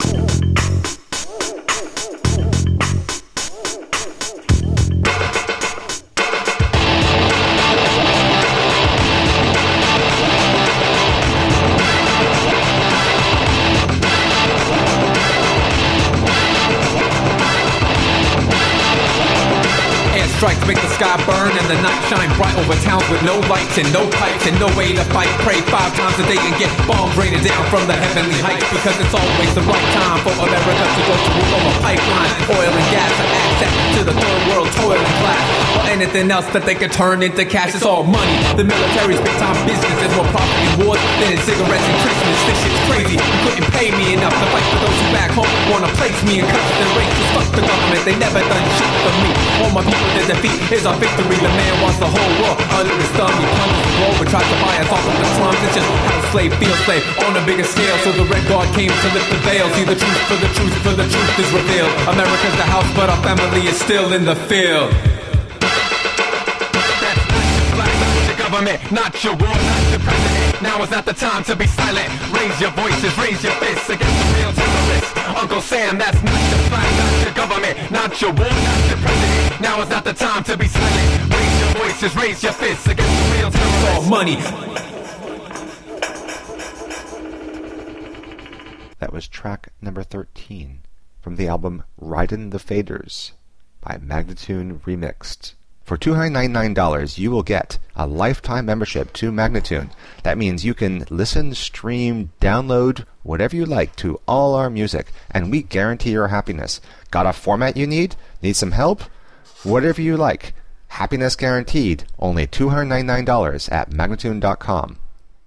Electronica, Rock, Alt Rock, Remix